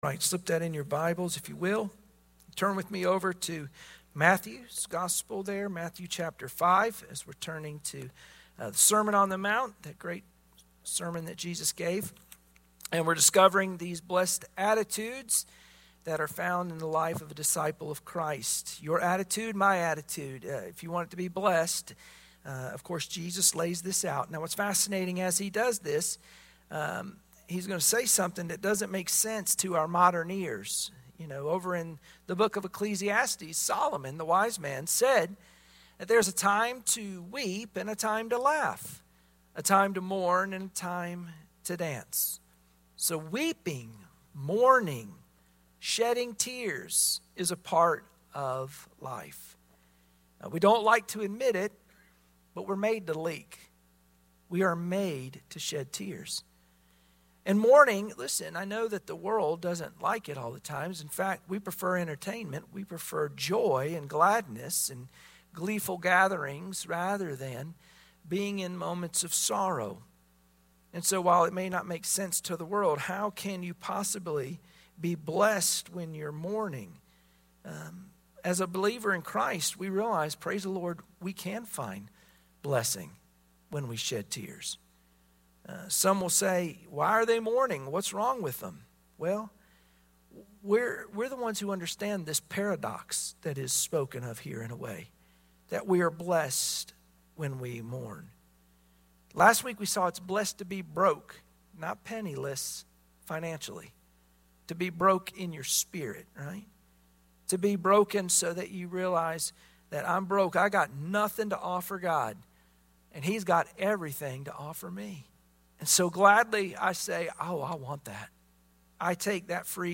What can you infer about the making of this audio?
Wednesday Prayer Mtg Passage: Matthew 5:4 Service Type: Wednesday Prayer Meeting Share this